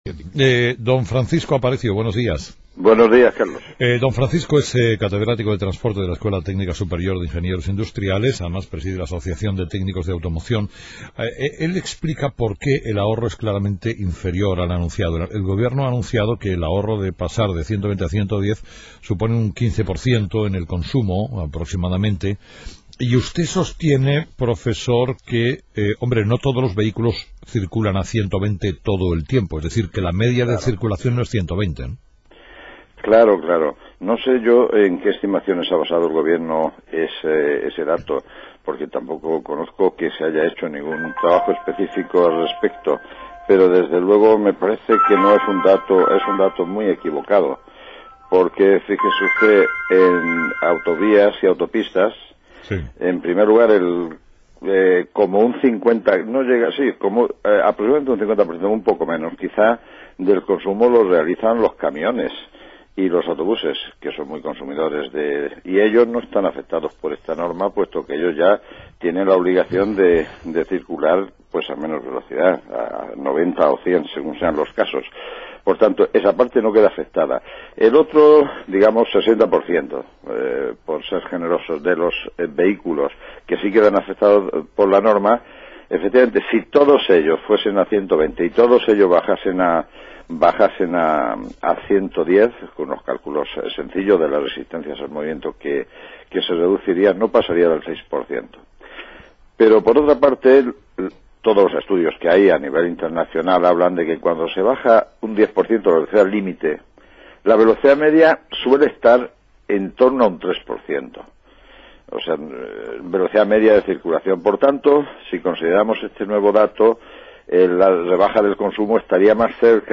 Entrevista con Miguel Sebastián
Entrevistado: "Miguel Sebastián"